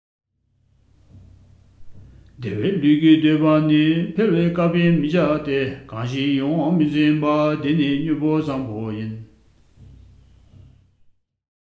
Karma_BCA_Chapter_8_verse_177_with_music.mp3